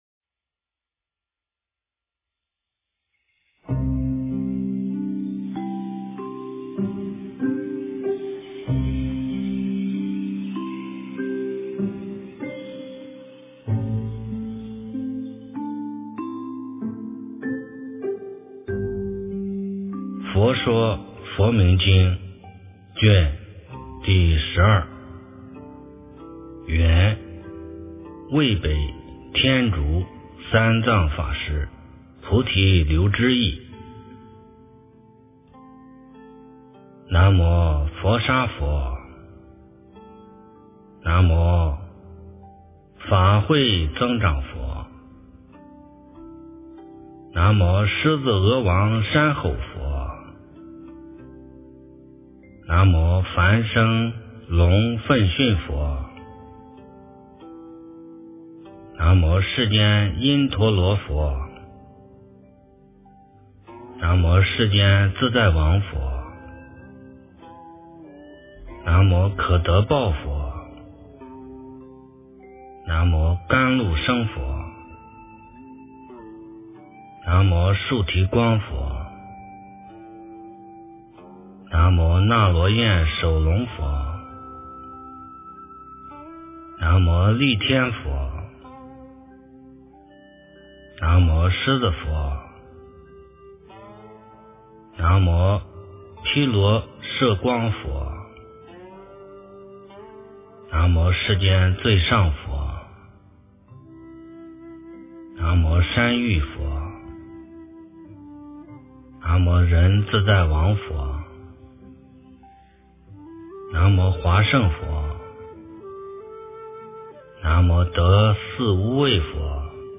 诵经
佛音 诵经 佛教音乐 返回列表 上一篇： 万佛名经第09卷 下一篇： 万佛名经第07卷 相关文章 自如--般若海合唱团 自如--般若海合唱团...